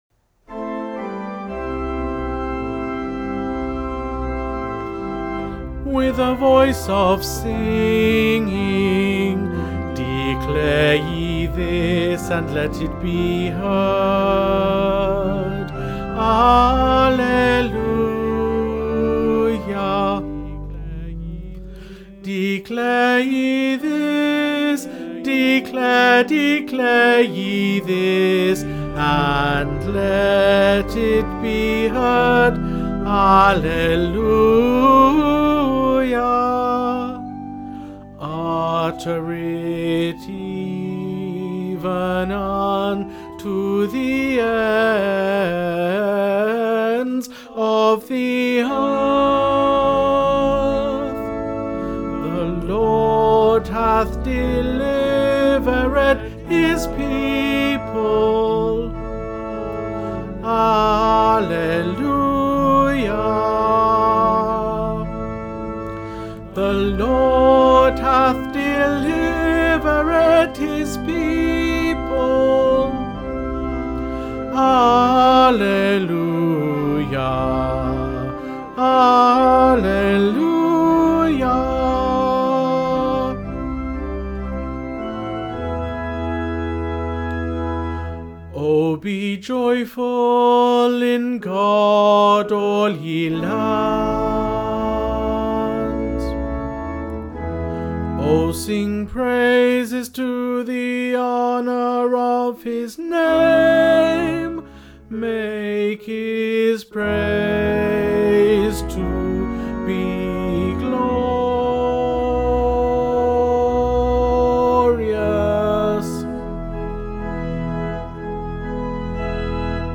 with a voice tenor 2 - Rame Peninsula Male Voice Choir
with a voice tenor 2
with-a-voice-tenor-2.mp3